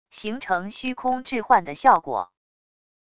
形成虚空置换的效果wav音频